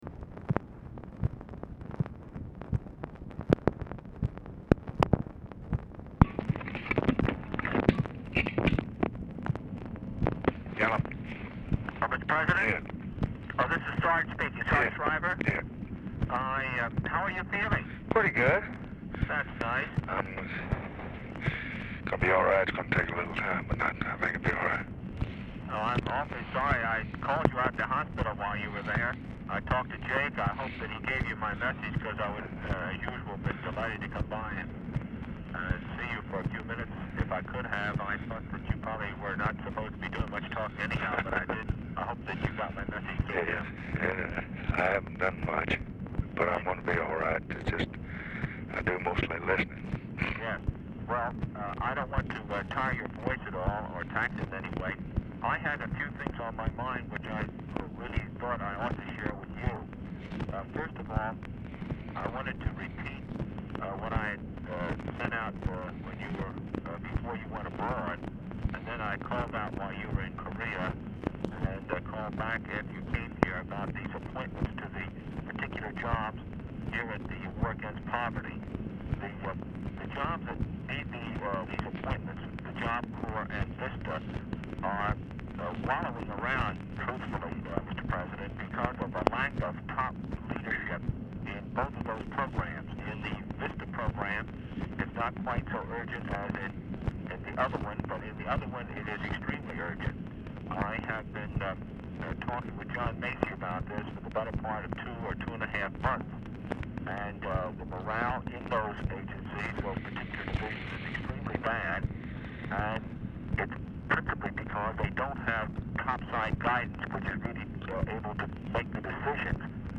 Telephone conversation # 11047, sound recording, LBJ and SARGENT SHRIVER, 11/22/1966, 12:17PM | Discover LBJ
Format Dictation belt
Location Of Speaker 1 LBJ Ranch, near Stonewall, Texas